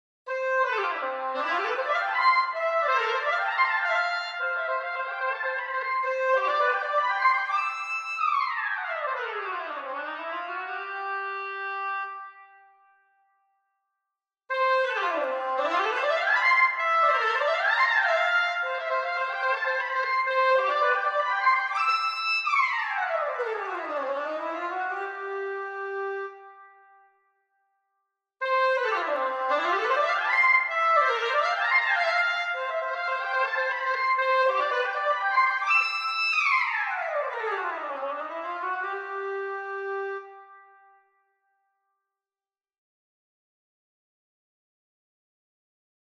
I created a run which was played with different woodwind instruments.
You will make out always the same pattern
1. Runs and the rest of the part are played with Perf Leg
2. Runs and the rest of the part are played with Perf Leg fa
3. Runs and the rest of the part are played with Perf Trills samples. (Ext. Version?)
LegRuns_3Oboes.mp3